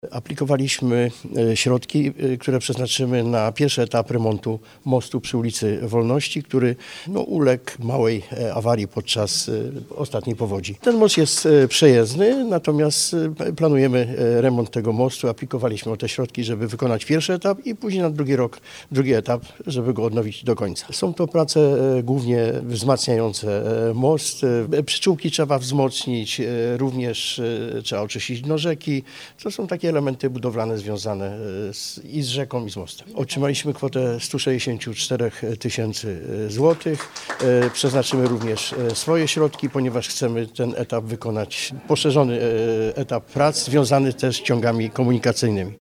Miasto Chojnów otrzymało 164 tys. zł na pierwszy etap remontu mostu przy ul. Wolności, który ucierpiał w czasie ubiegłorocznej powodzi. Przedstawia Jan Serkies, burmistrza Chojnowa.